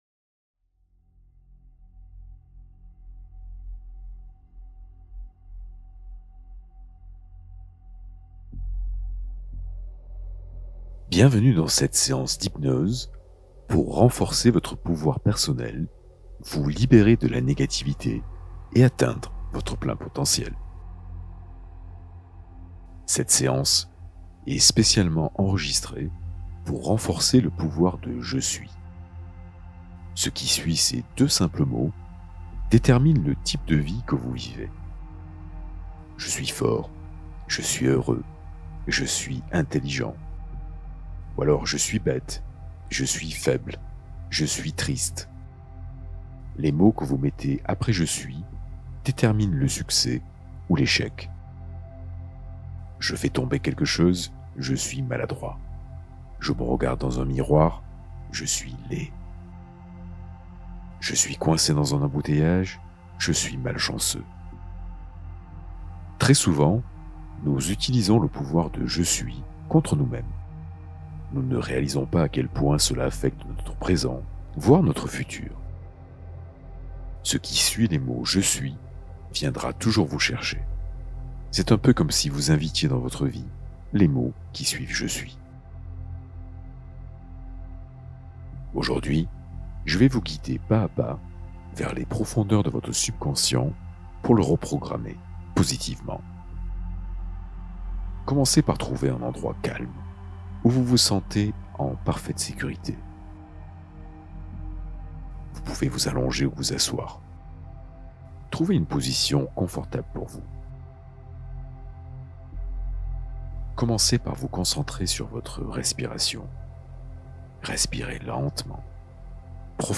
Hypnose d’amélioration personnelle pour renforcer ton pouvoir intérieur